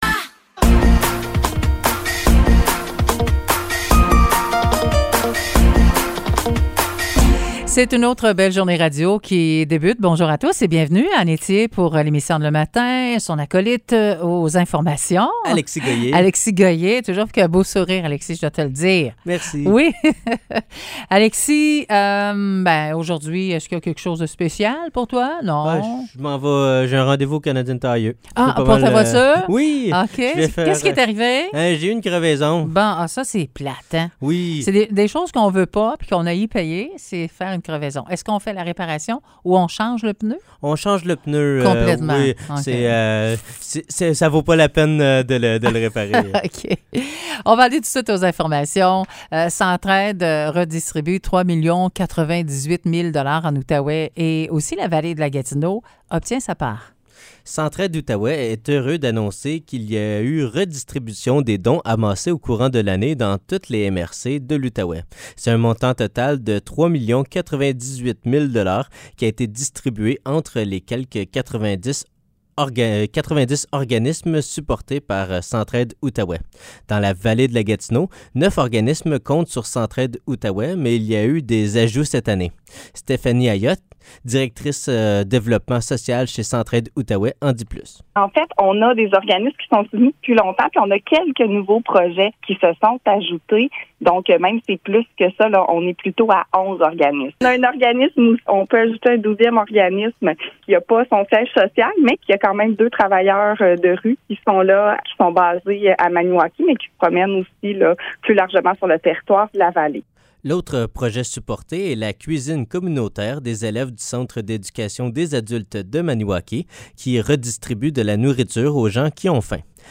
Nouvelles locales - 20 juillet 2023 - 9 h